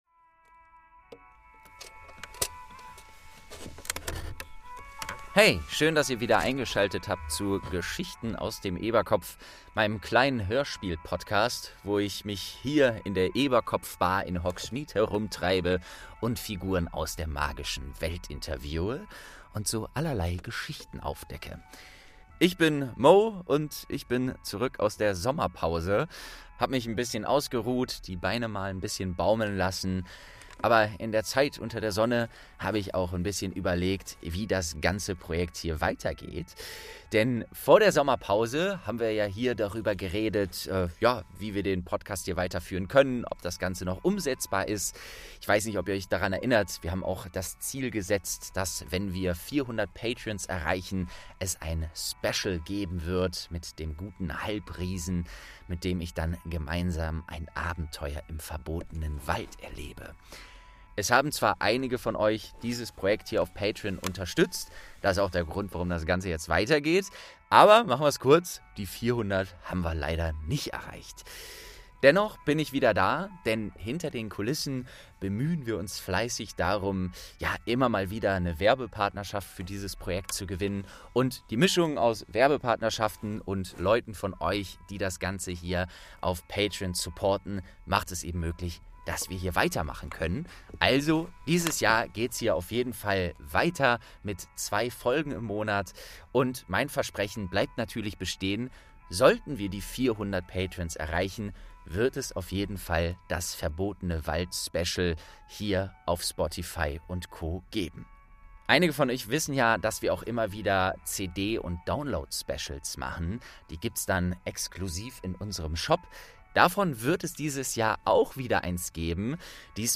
19. Mr. St. Mungo Sir! | St. 2 ~ Geschichten aus dem Eberkopf - Ein Harry Potter Hörspiel-Podcast Podcast